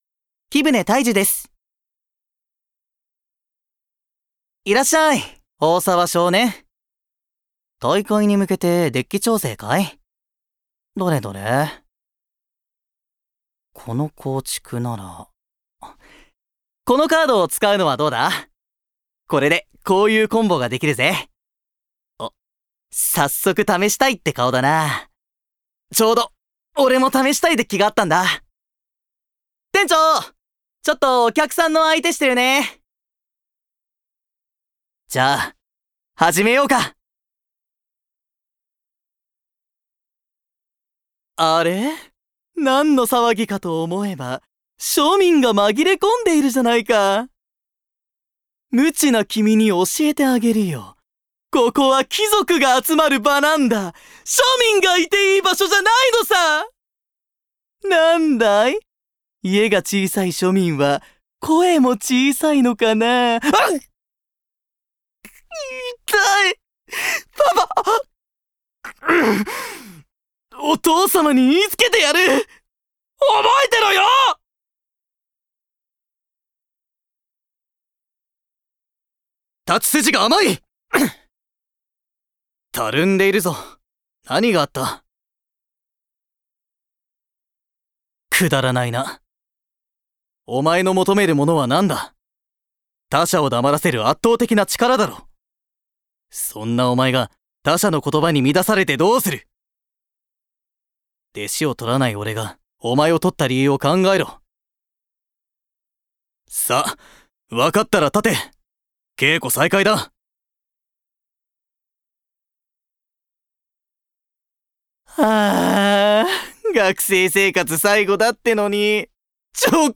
音域： A♭～A♭
VOICE SAMPLE